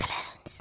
Neptunes Woman SFX.wav